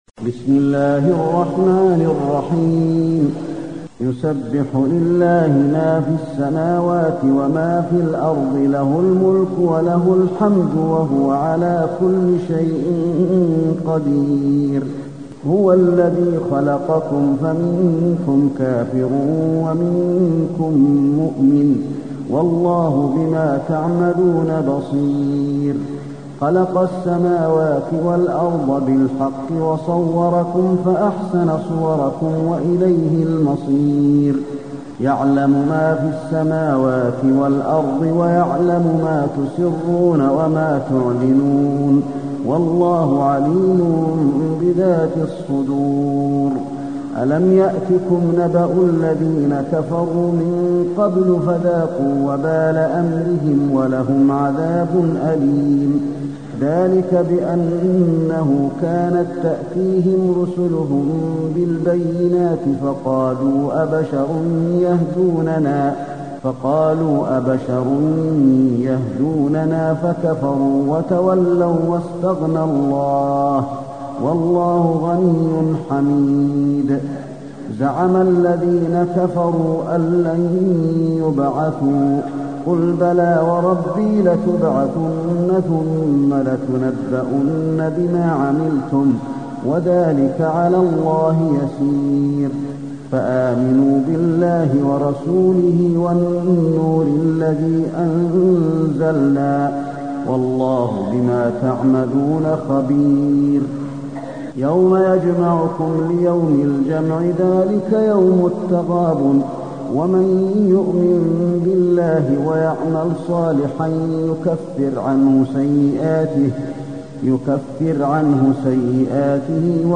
المكان: المسجد النبوي التغابن